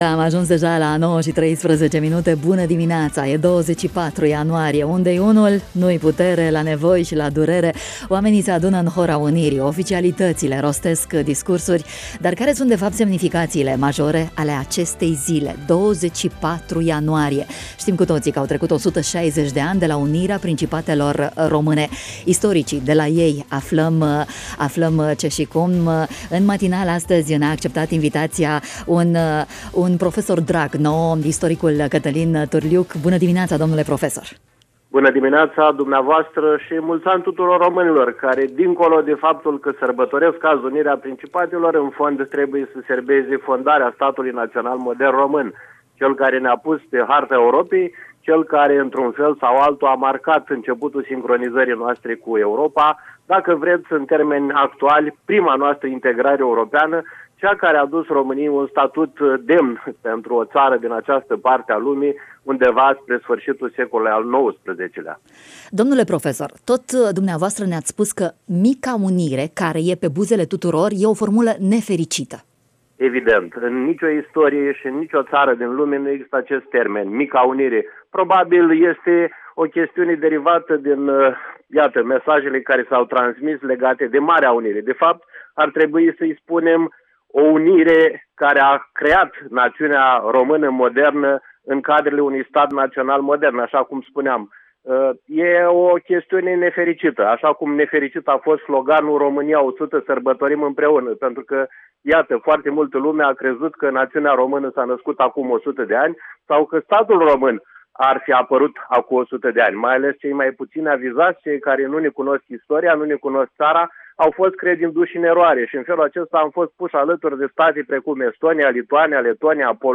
în matinalul Radio România Iaşi